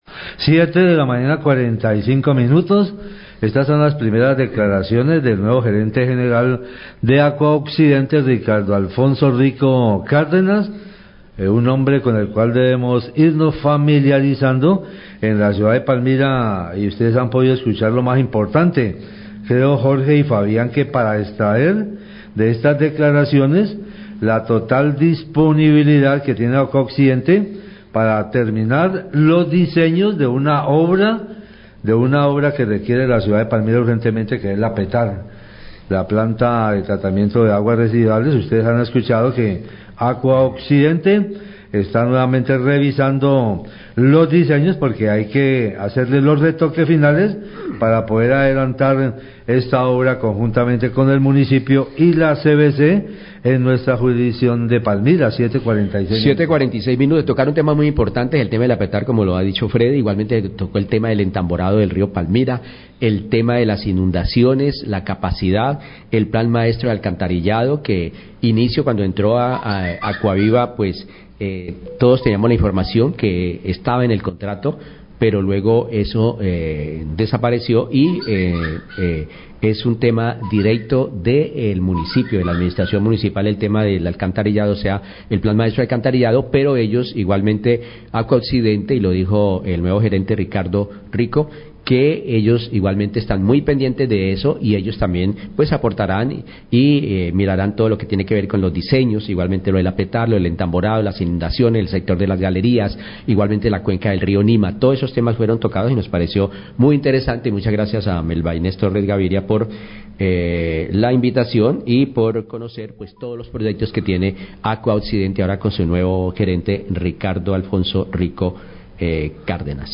Periodistas comentan disponilibilidad de Aquaoccidente para terminar diseños de PTAR Palmira
Radio